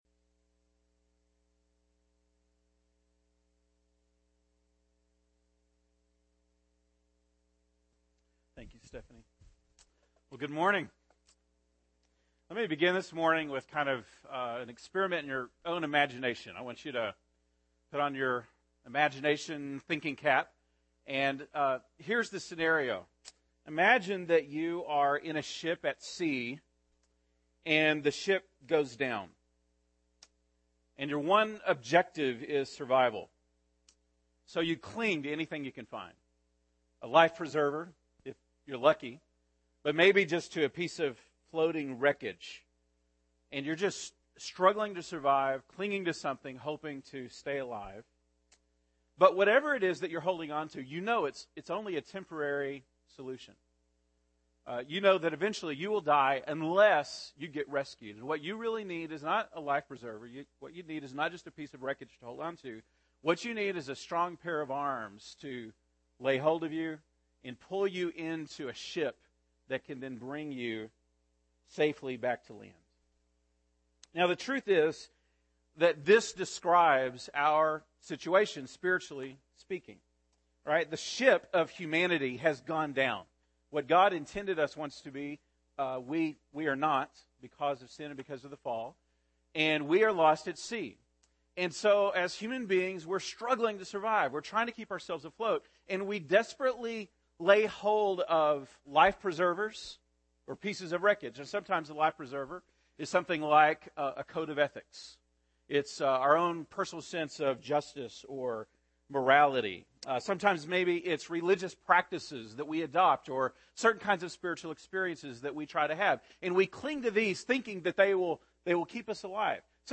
February 17, 2013 (Sunday Morning)